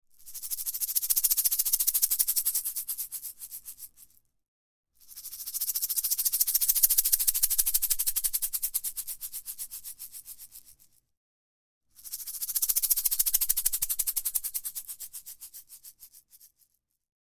3-egg-shaker-rolls-32718.mp3